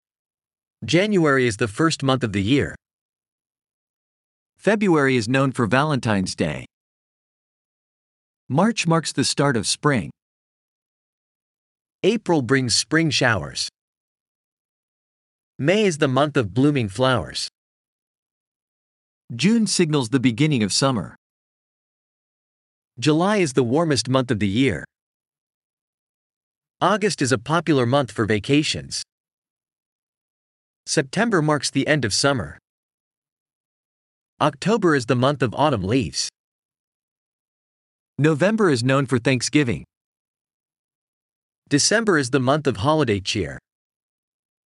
Ayların isimlerini daha iyi öğrenebilmeniz için cümle örnekleri verilmiştir. Ayrıca İngilizce ayların isimlerini doğru telaffuz edebilmeniz için mp3 formatında ses dosyası eklenmiştir.